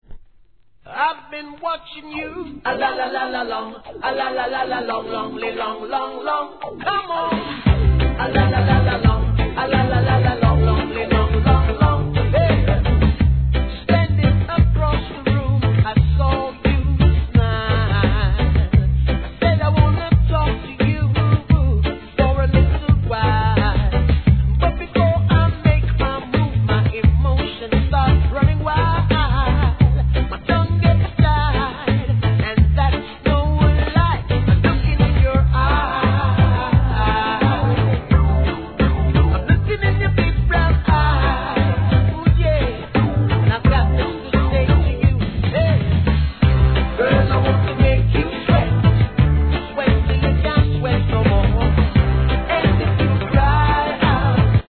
HIP HOP/R&B
お馴染のサビ・フレーズは一度耳にすると頭から離れないキャッチーさ! 1993年大ヒット!!